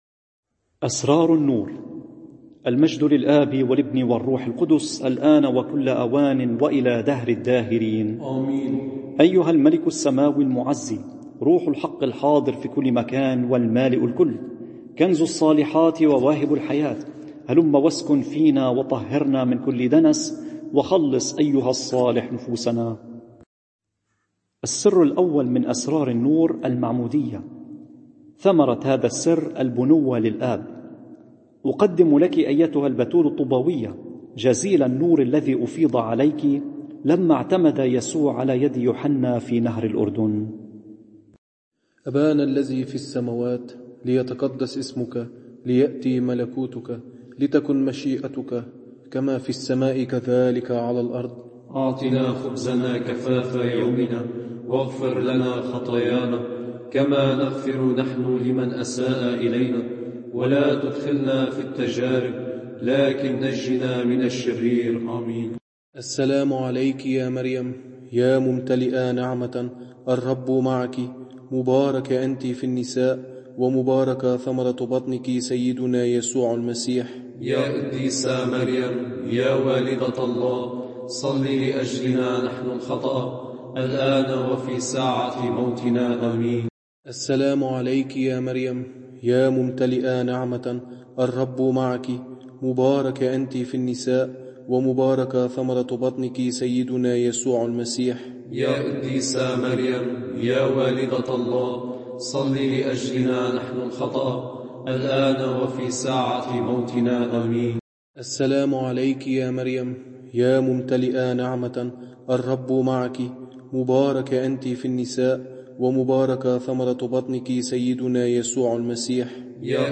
نور ونار لشرح الإنجيل المقدّس، عظات، مواضيع وأحاديث روحيّة، عقائديّة ورهبانيّة…